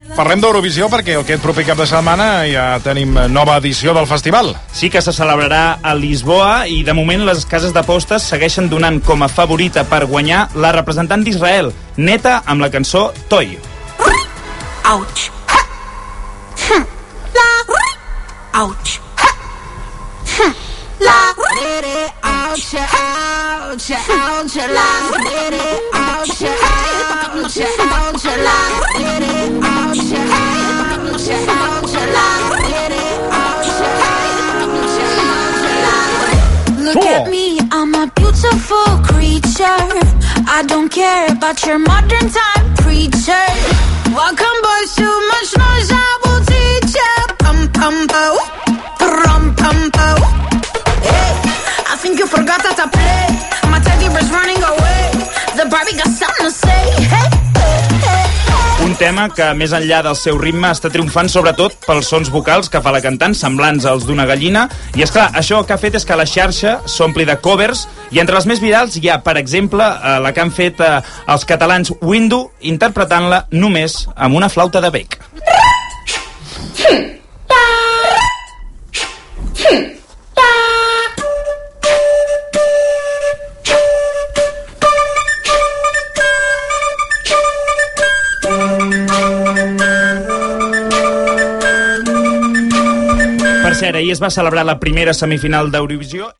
recorder quartet